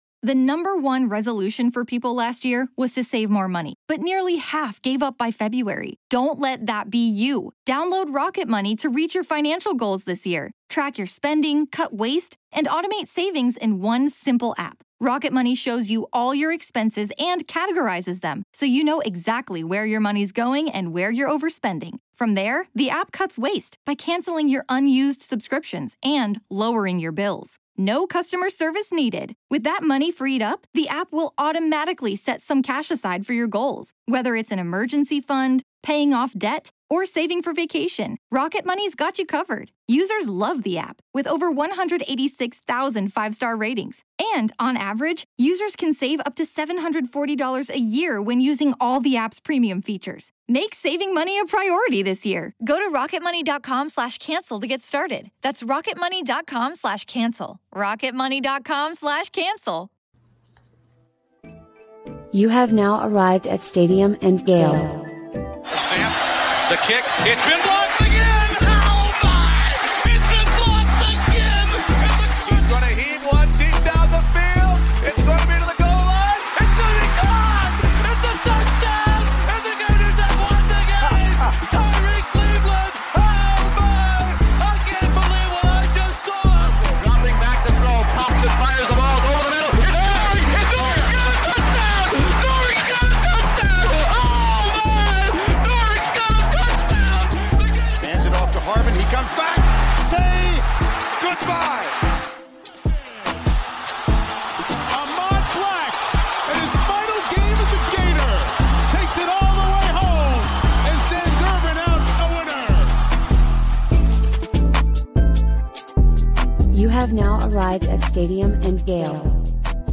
🎧 Stadium and Gale delivers interviews, analysis, and laughs — all focused on Florida athletics.